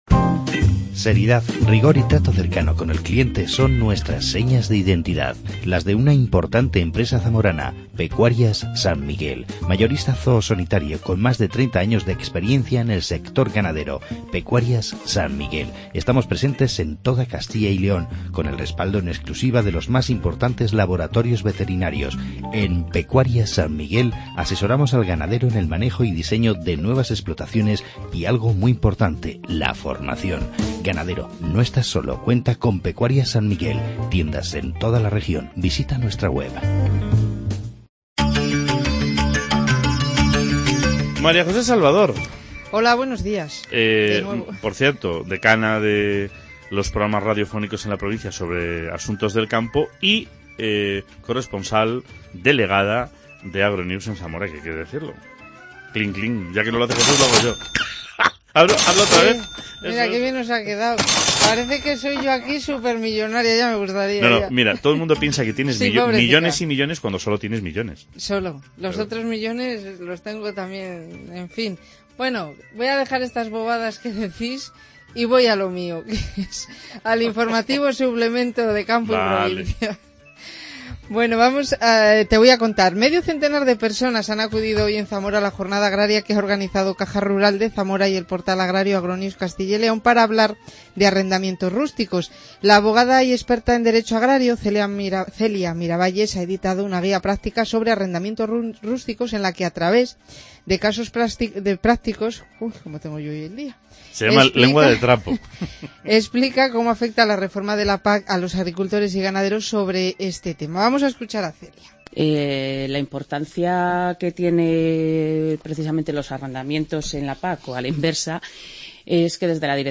Presentación de la Jornada Agraria